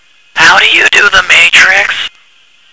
phone_matrix.wav